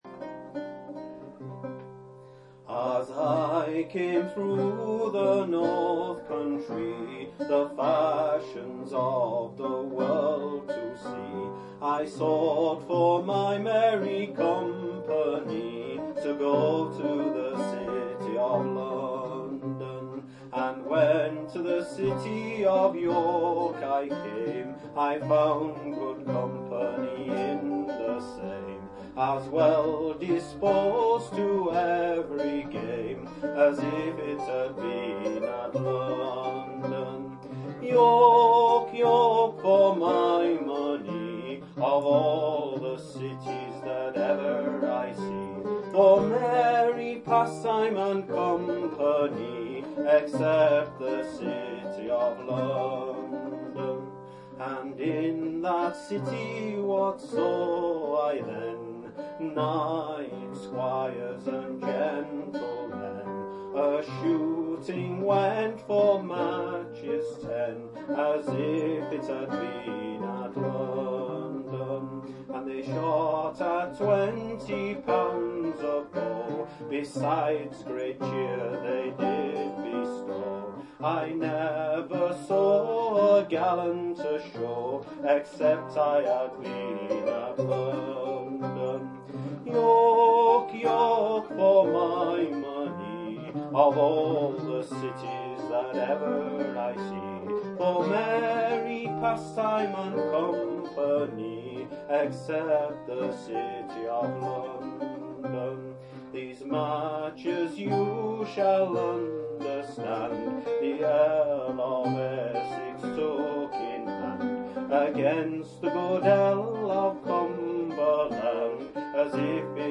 Lute
Harrogate
C minor